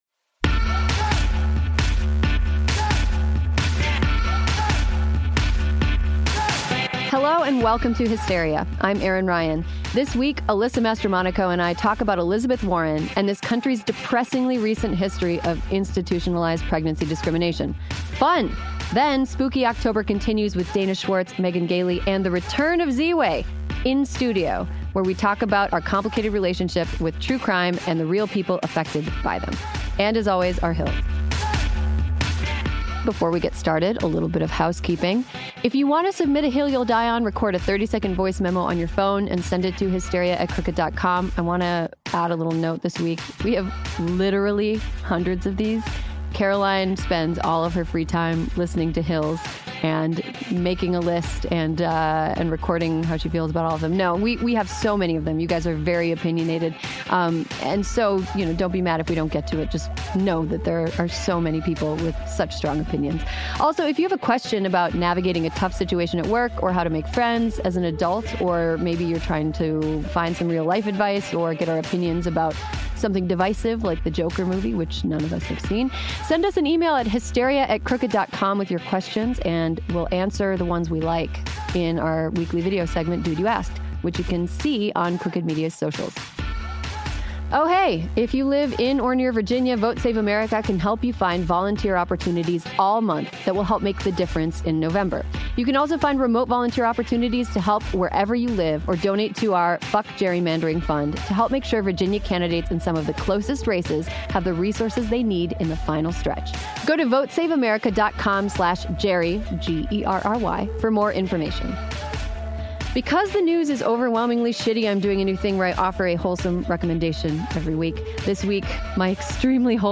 and the return of Ziwe in studio to talk about our complicated relationship with True Crime and the real people affected by them.